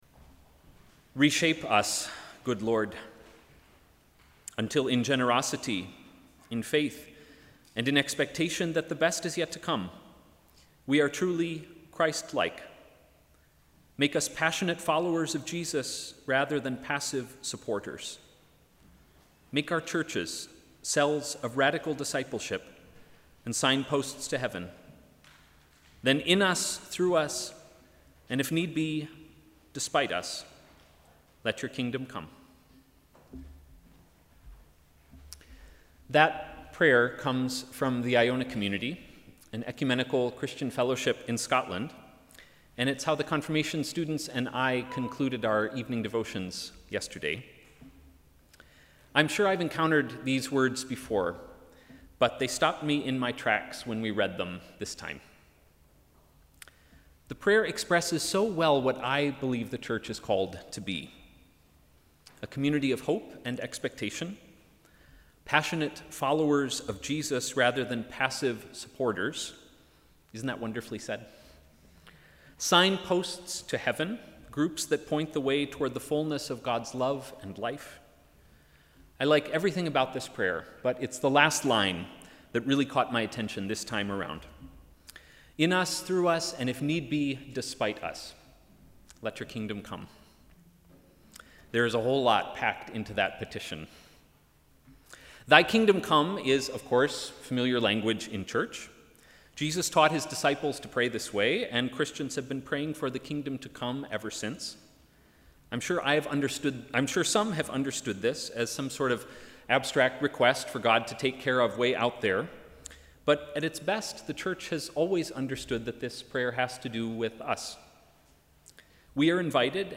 Sermon: ‘Without hindrance’
Sixth Sunday of Easter 2024